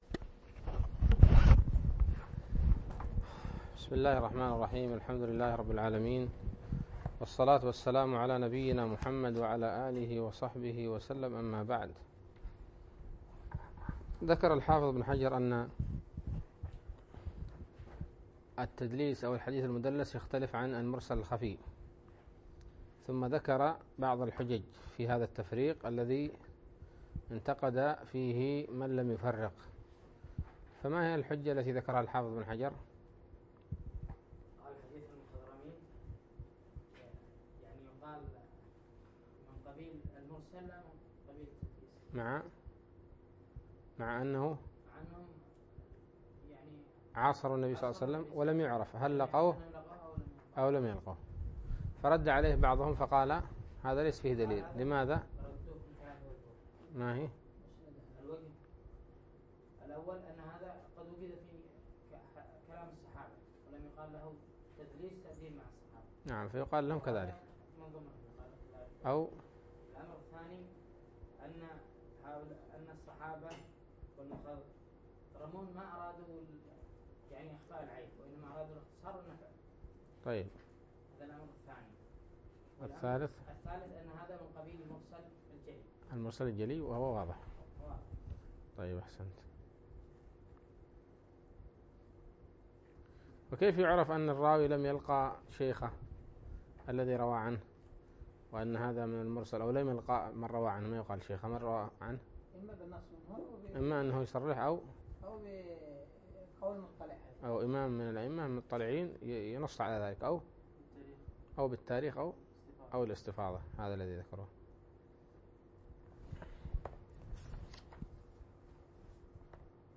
الدرس الثاني والعشرون من شرح نزهة النظر